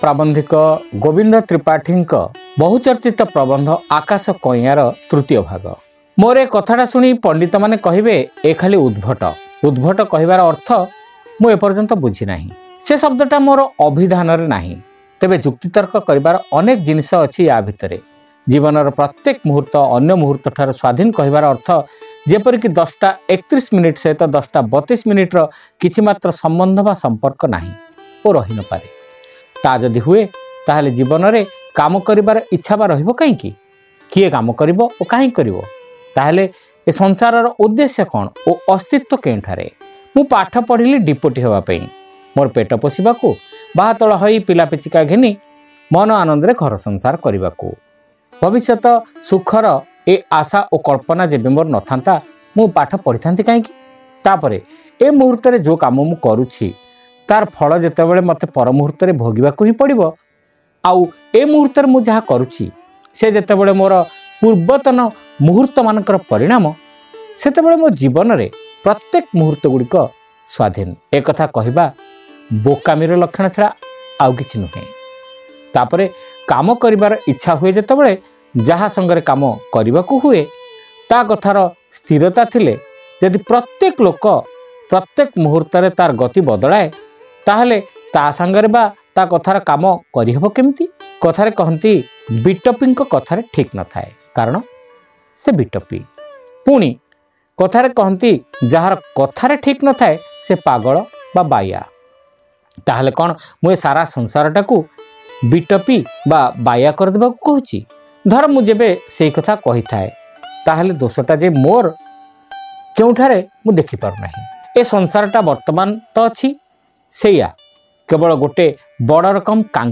Audio Story : Akasha Kainyan (Part-3)